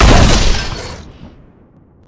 shoot3.ogg